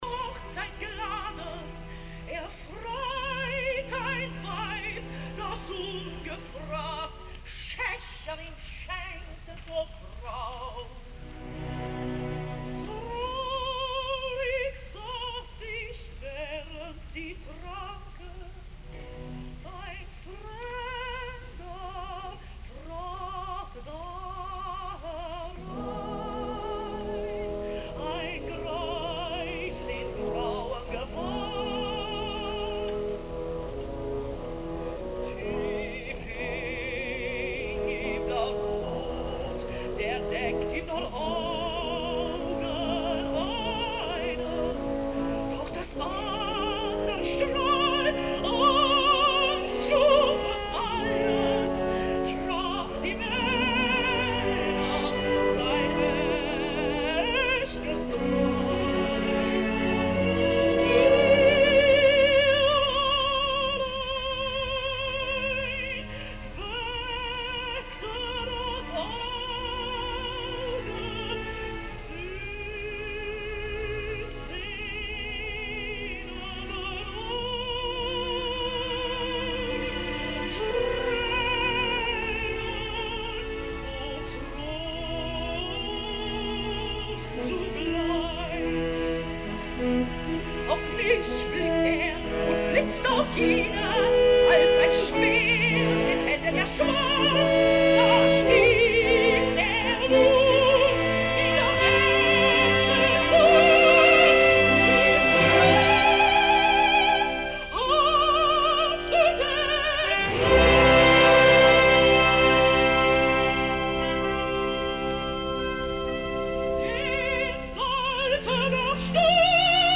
Abbiamo attinto a registrazioni dal vivo che dagli Stati Uniti sono davvero copiose.